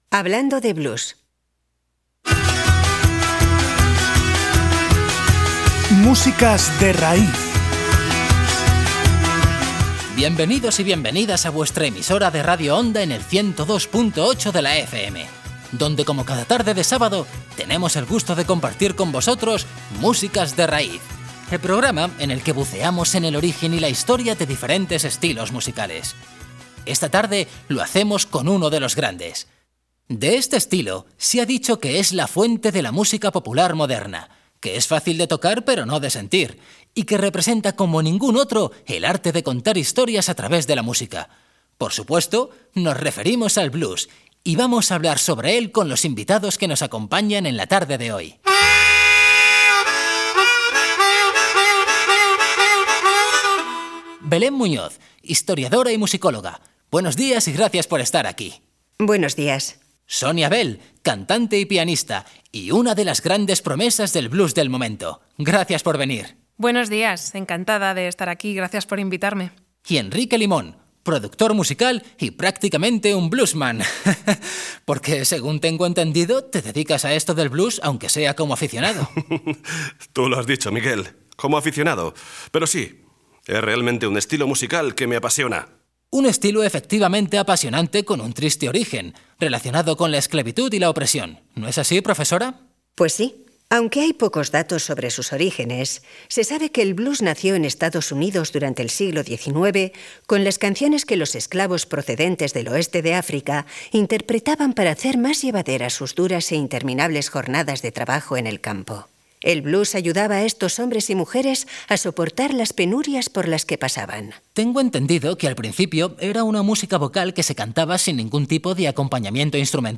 programa de radio.